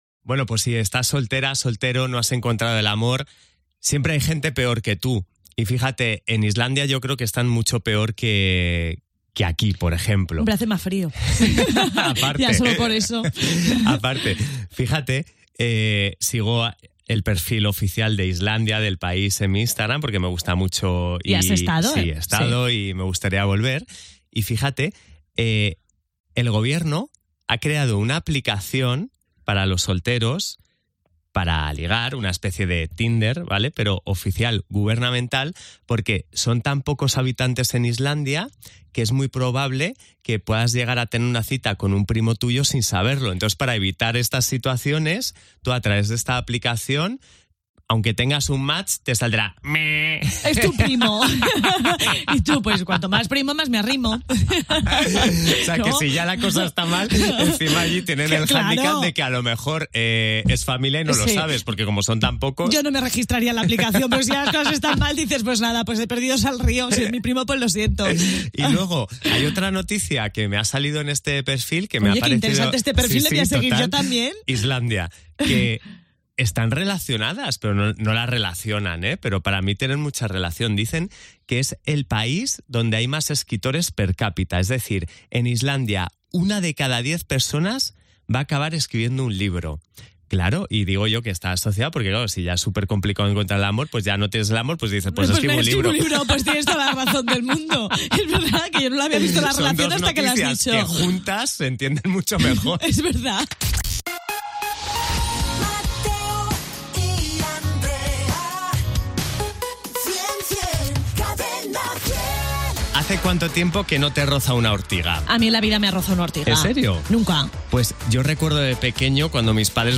Indicatiu del programa i de la ràdio.
Entreteniment